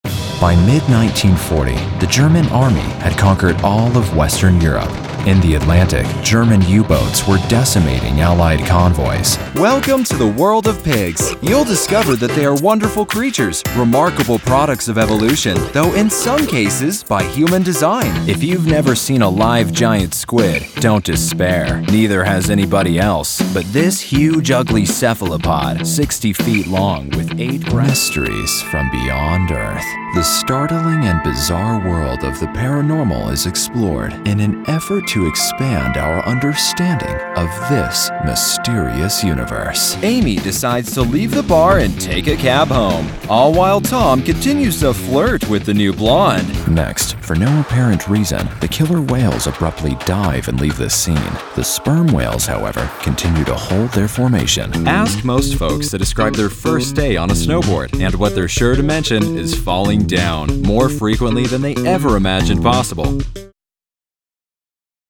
Conversational, Real, Edgy, Versatile, Professional, Cool, Hip, Compelling, Funny, Narrator
Sprechprobe: Sonstiges (Muttersprache):